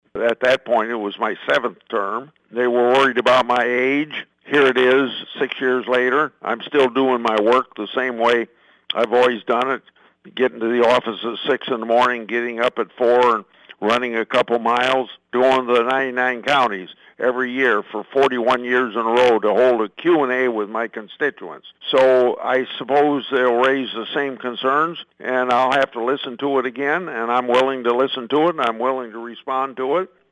Grassley made his comments during a conference call with reporters Monday.